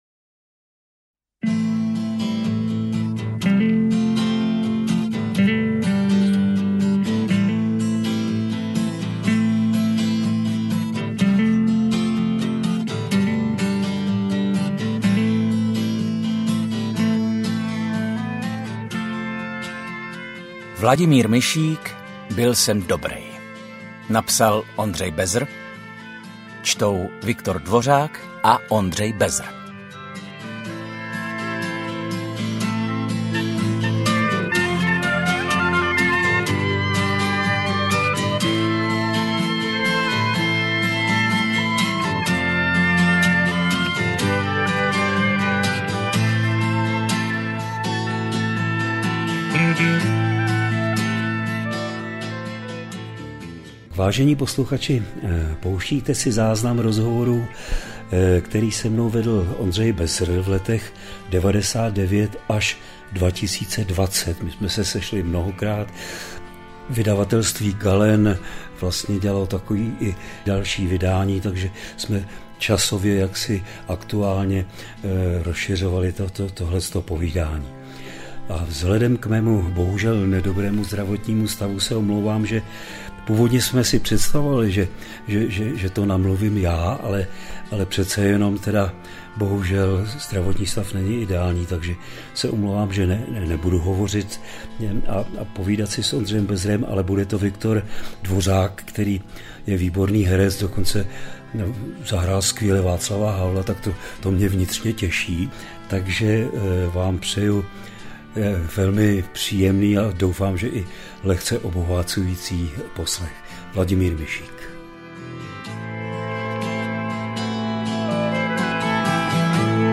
Životní příběh Vladimíra Mišíka poprvé v audioknize